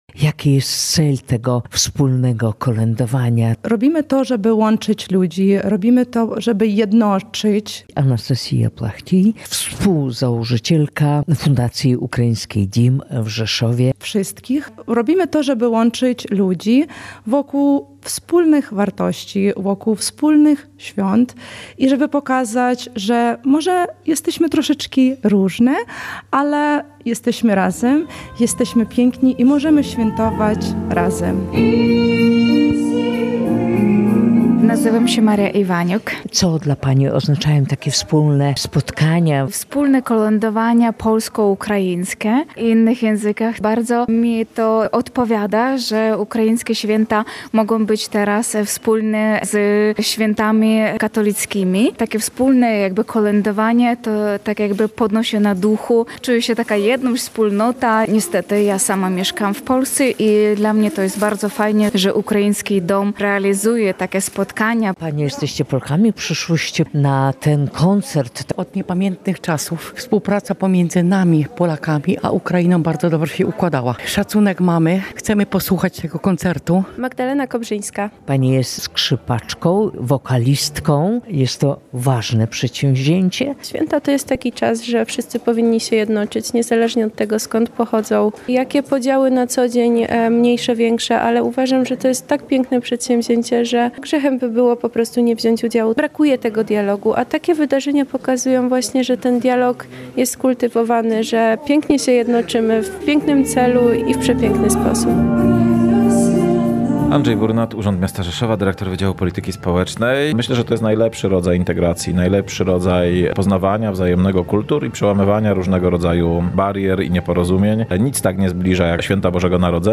W hotelu prezydenckim w Rzeszowie odbył się Międzykulturowy festiwal Dim FEST Kolada. Na scenie zabrzmiały kolędy w języku ukraińskim, polskim i niemieckim.